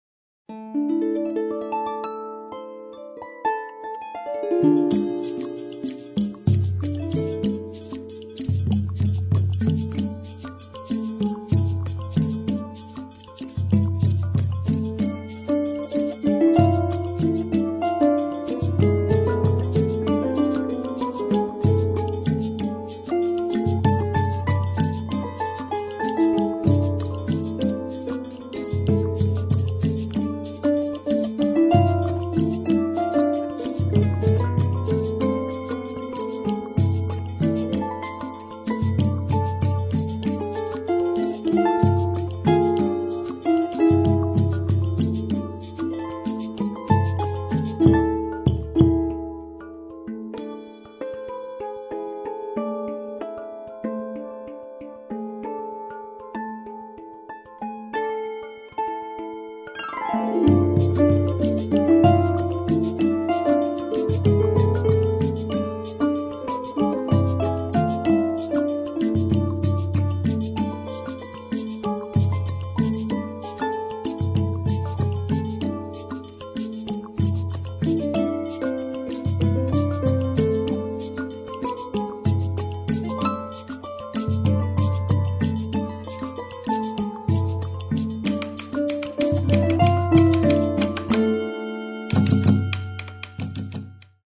Improharfe
Hang & Harfe